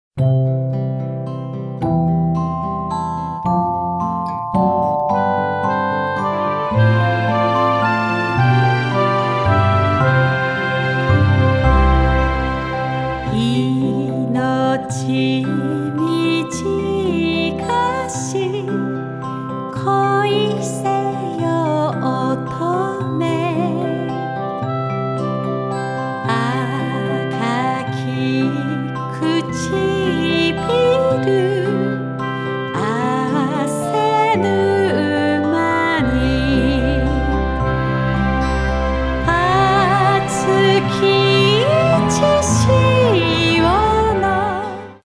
カバーアルバム
四季折々の美しい風景や豊かな心情が綴られた名曲を、心に寄り添うように優しく温かく歌い上げます。